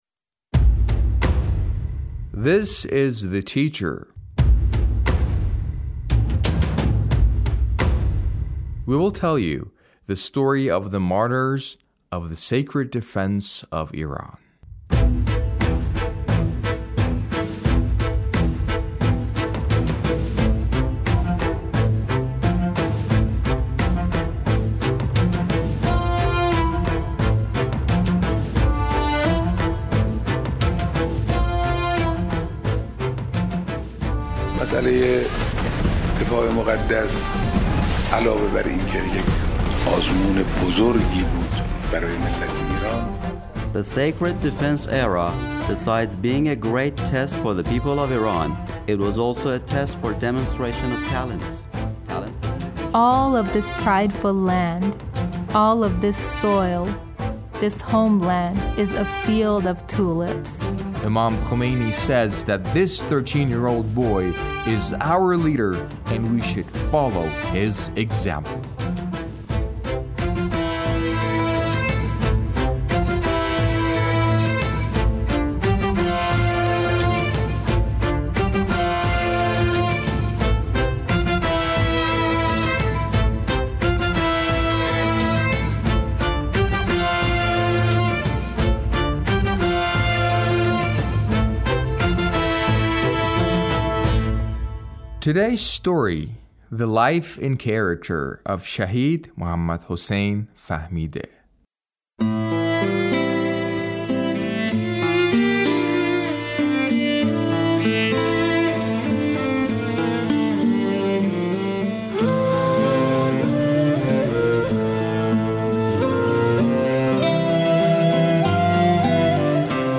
A radio documentary on the life of Shahid Muhammad Hussain Fahmideh